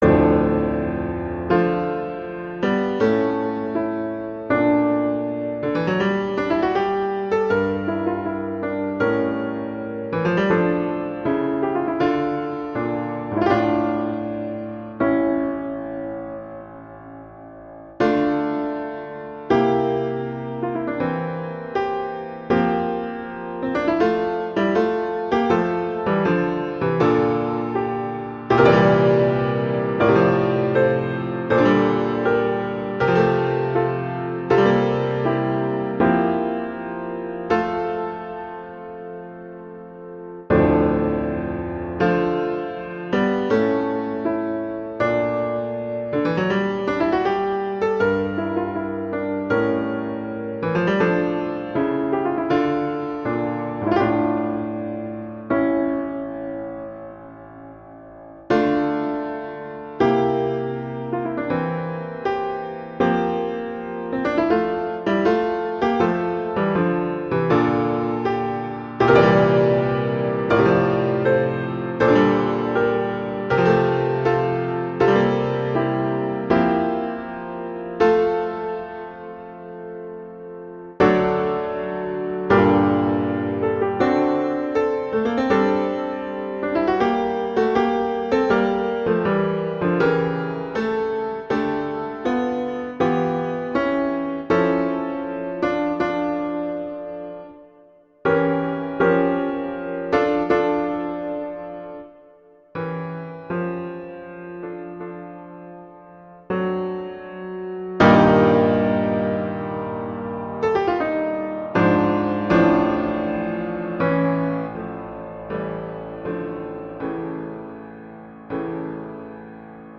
piano solo
classical
Largo e sostenuto